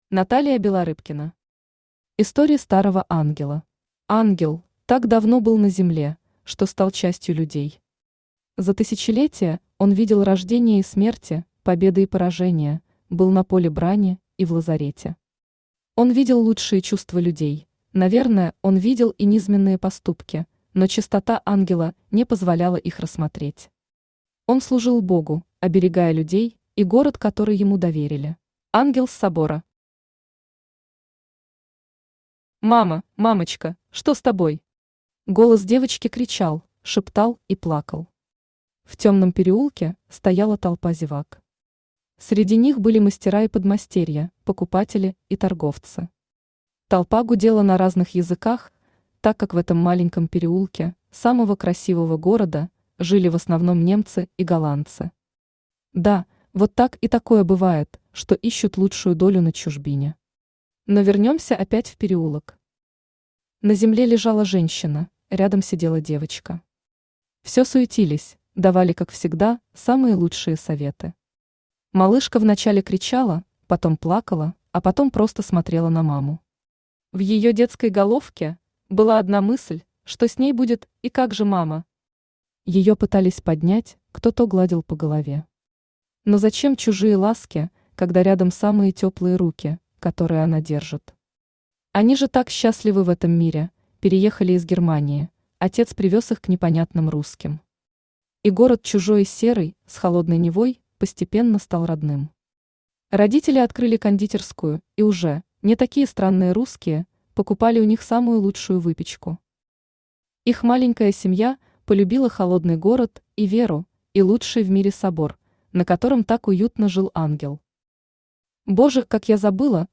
Аудиокнига Истории Старого ангела | Библиотека аудиокниг
Aудиокнига Истории Старого ангела Автор Наталия Вячеславовна Белорыбкина Читает аудиокнигу Авточтец ЛитРес.